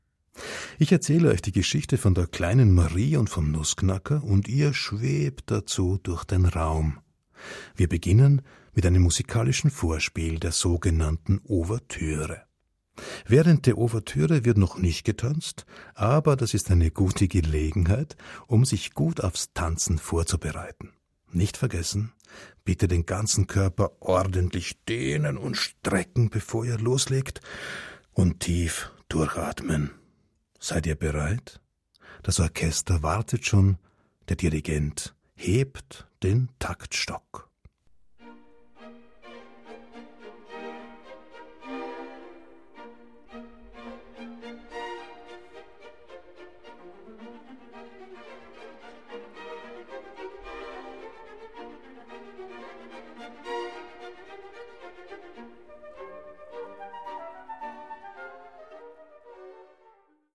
Der Nussknacker (Mein erstes Musikbilderbuch mit CD und zum Streamen, Bd.) Märchenballett nach Peter Iljitsch Tschaikowsky Heinz Janisch (Autor) Buch | Hardcover 24 Seiten 2016 | 5.